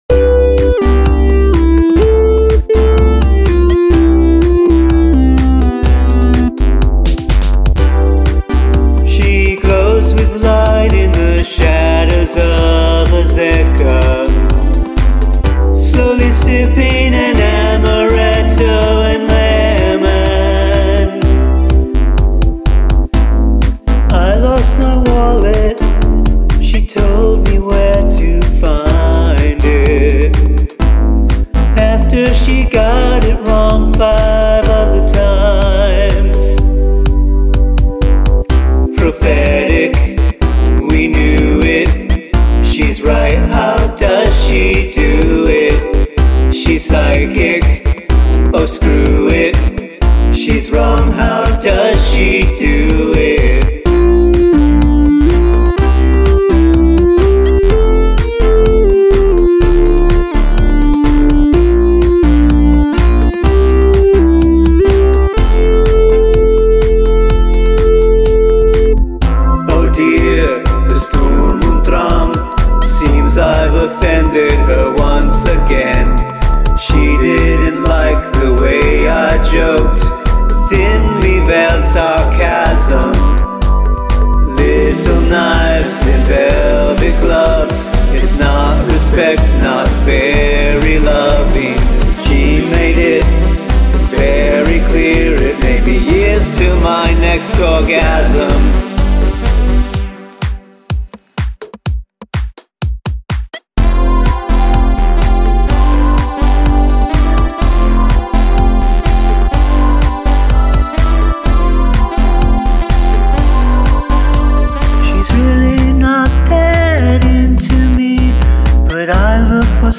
Three part song in less than three minutes